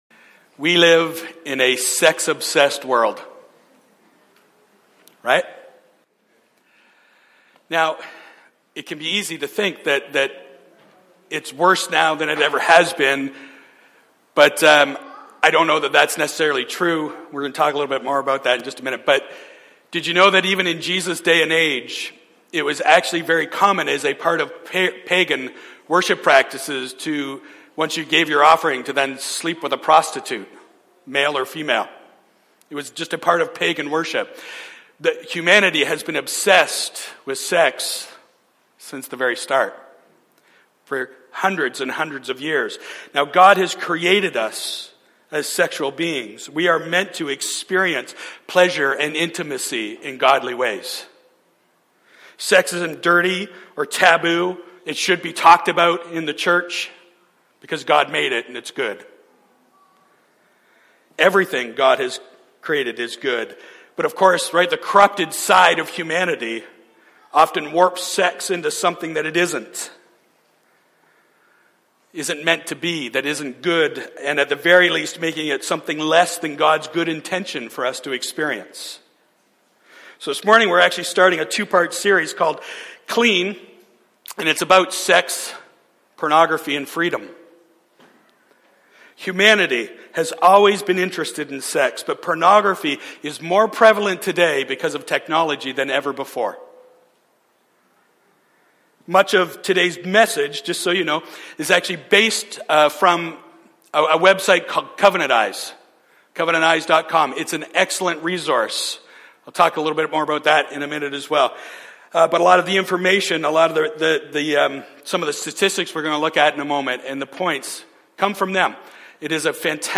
Sermons | Christian Life Fellowship